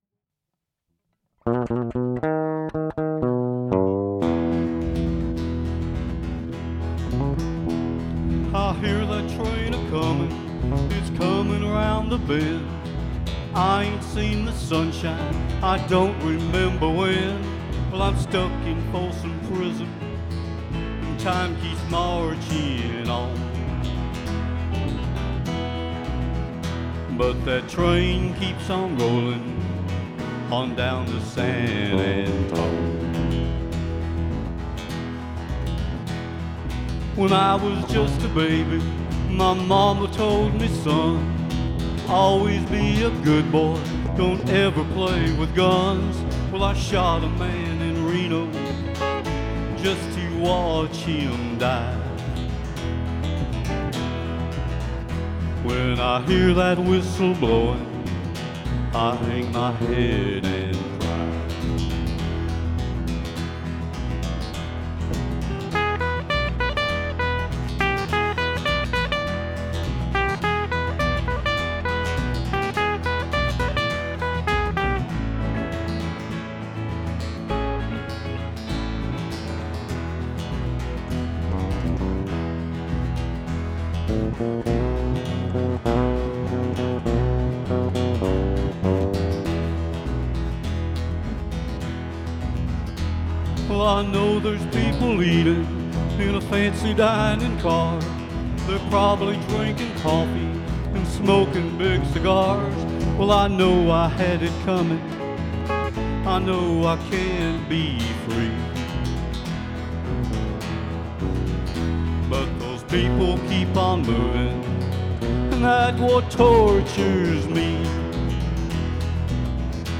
Traditional acoustic music, live and in person.